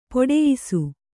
♪ poḍeyisu